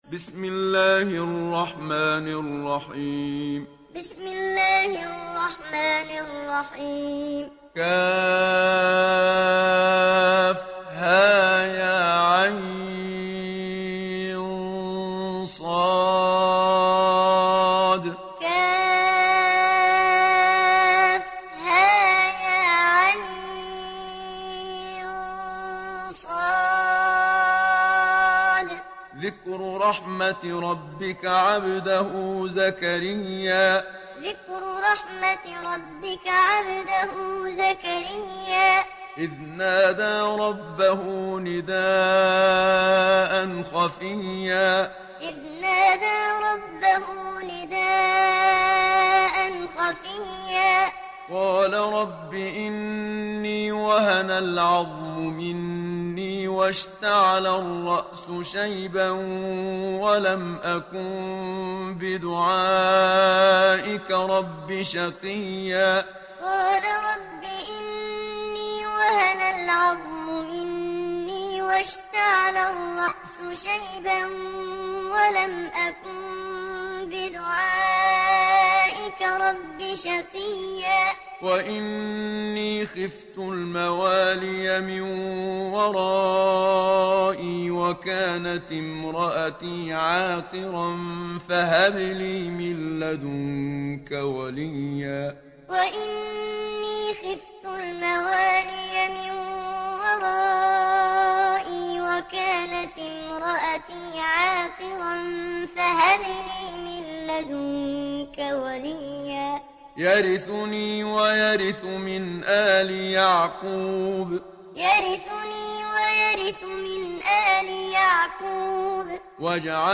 সূরা মারইয়াম mp3 ডাউনলোড Muhammad Siddiq Minshawi Muallim (উপন্যাস Hafs)